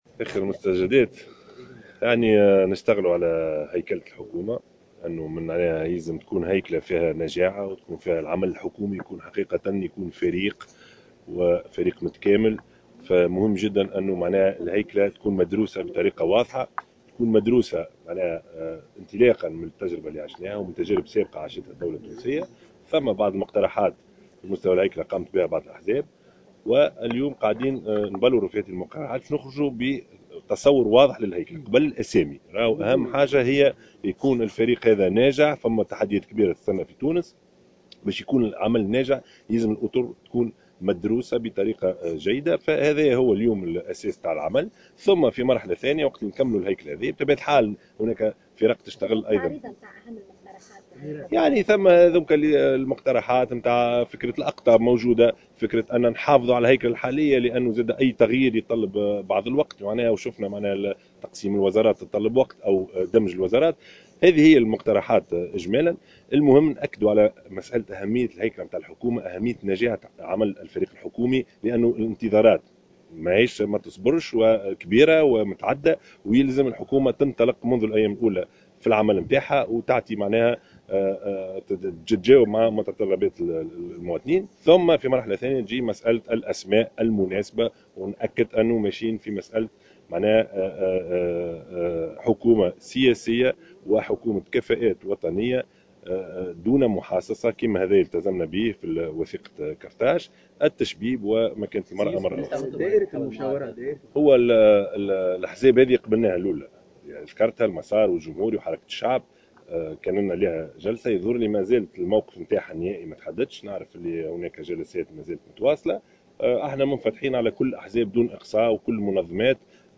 قال رئيس الحكومة المكلف، يوسف الشاهد، في تصريح إعلامي اليوم السبت، إنه يواصل النظر في مقترحات الأحزاب والمنظمات الوطنية بخصوص هيكلة الحكومة المرتقبة، بهدف تشكيل فريق حكومي "متكامل وناجع".